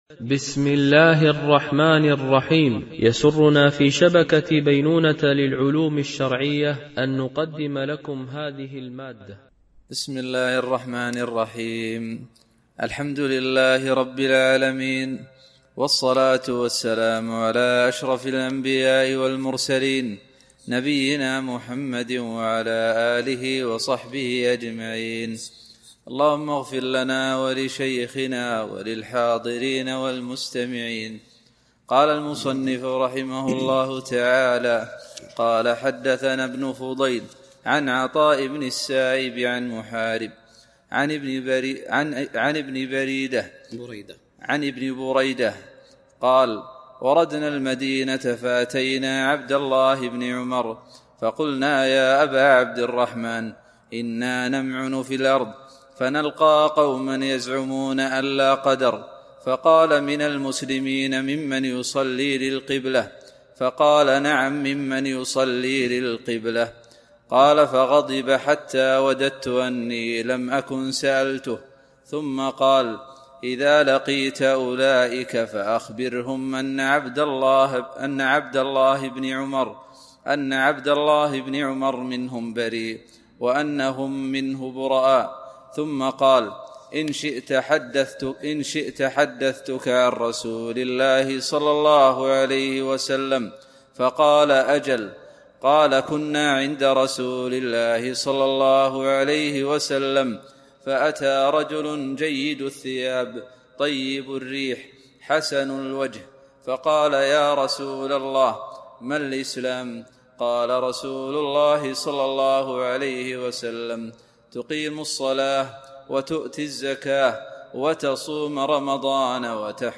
شرح كتاب الإيمان لابن أبي شيبة ـ الدرس 28
العنوان: شرح كتاب الإيمان لابن أبي شيبة ـ الدرس 28